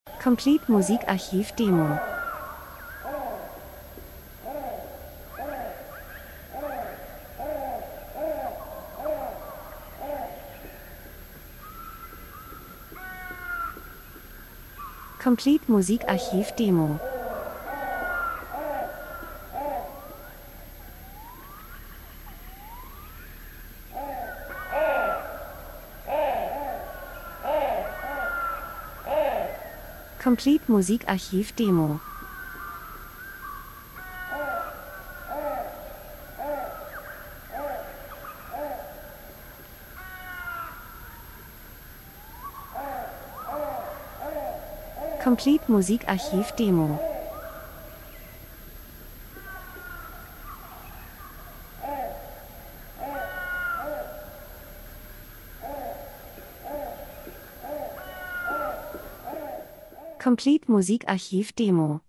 Herbst -Geräusche Soundeffekt Herbstwald, Vögel, Krähen 01:01